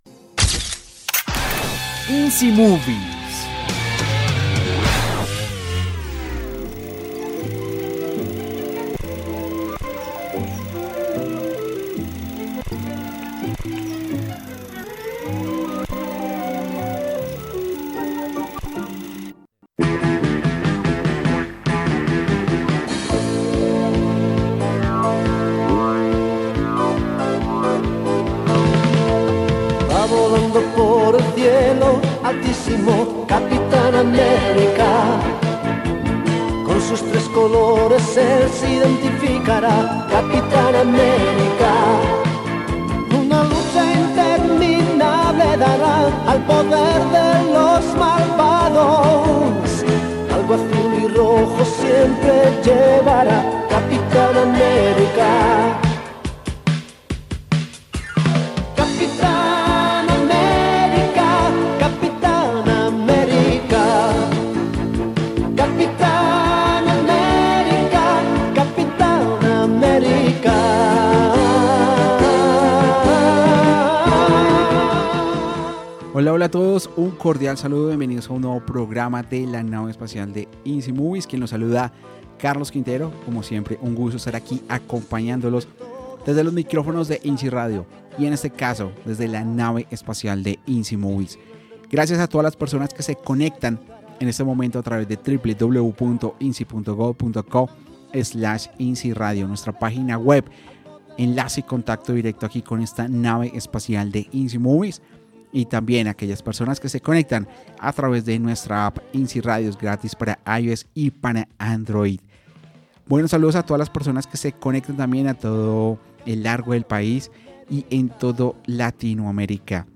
6575_PGM_INCI_MOVIES_COMPLETO_23_MAYO_2023_AUDIODESCRIPCION.mp3